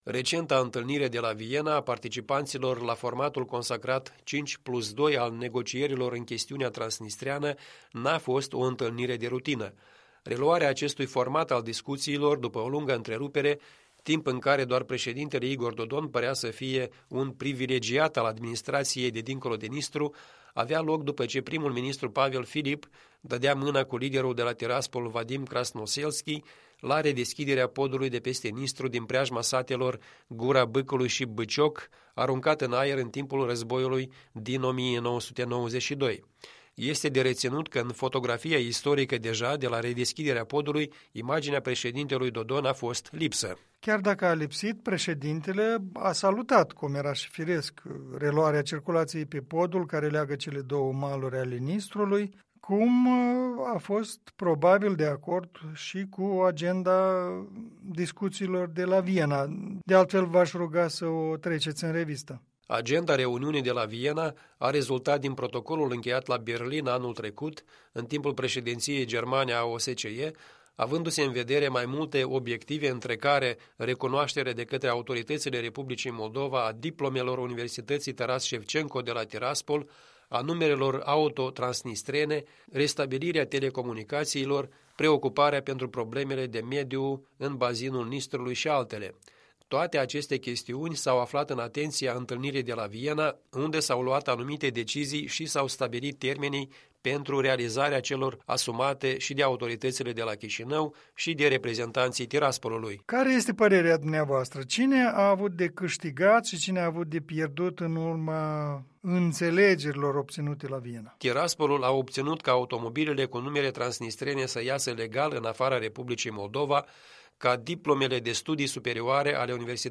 Un punct de vedere